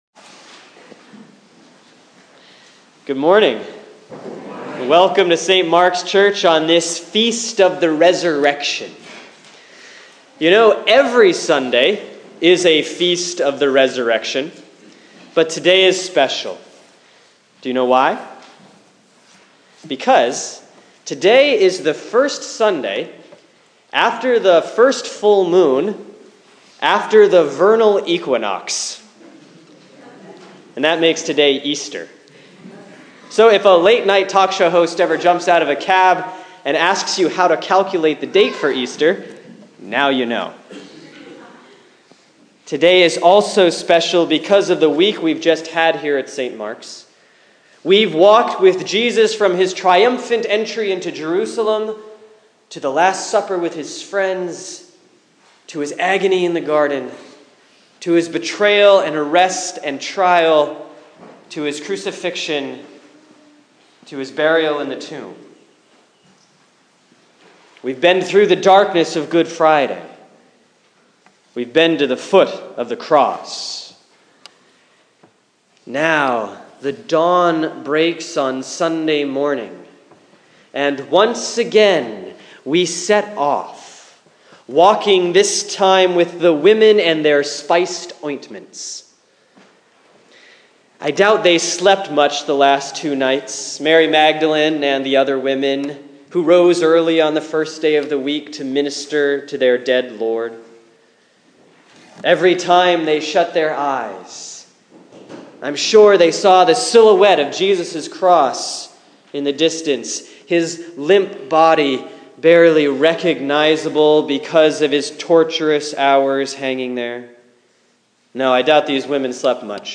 Sermon for Sunday, December 7, 2014 || Advent 2B || Mark 1:1-8